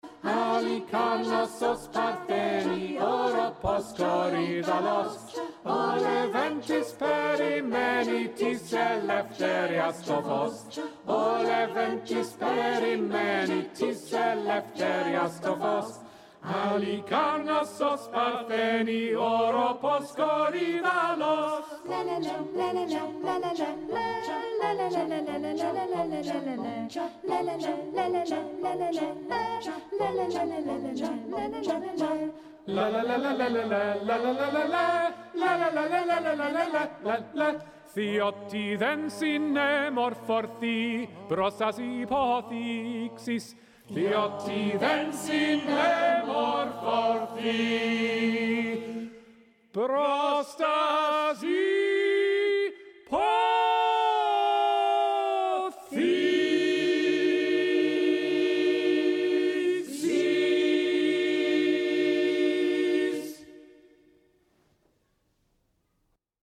calm and riotous, exultant and tranquil